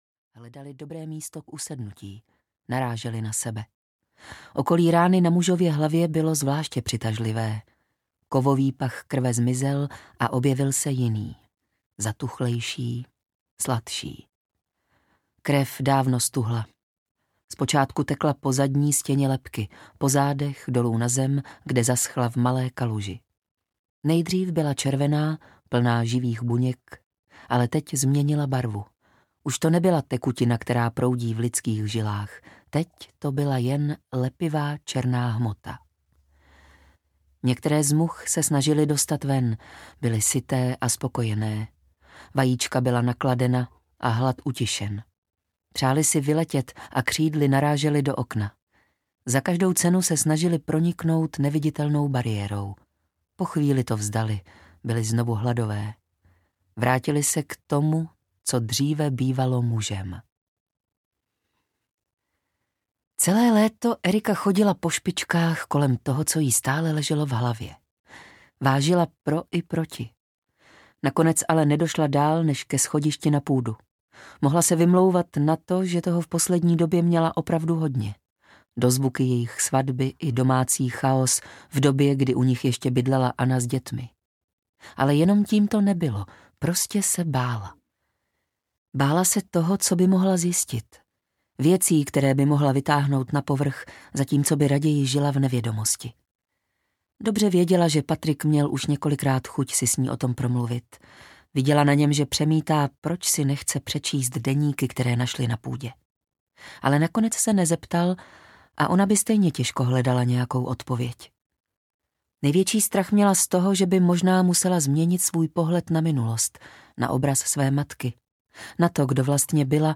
Elsyino tajemství audiokniha
Ukázka z knihy